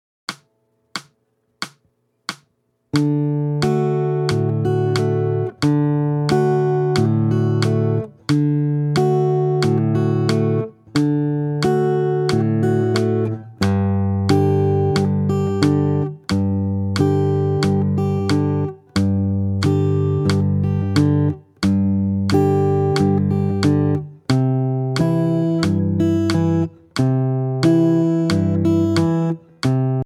Voicing: Guitarr w/